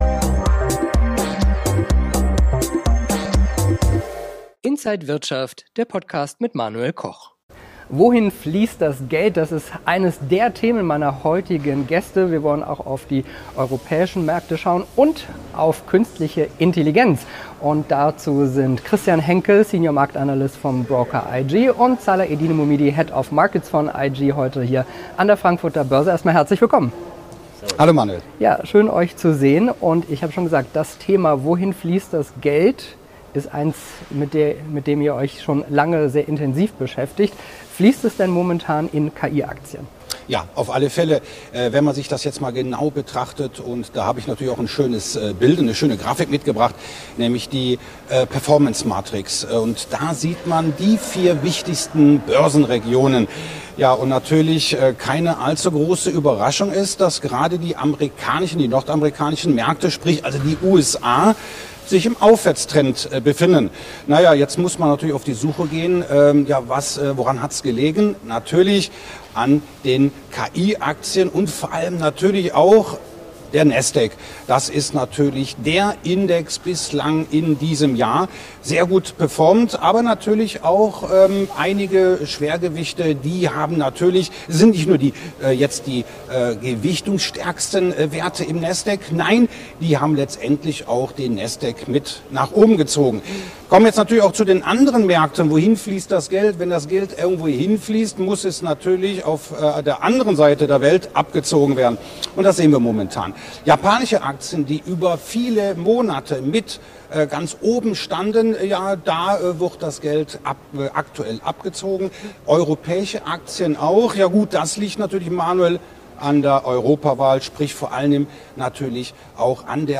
Interview
an der Frankfurter Börse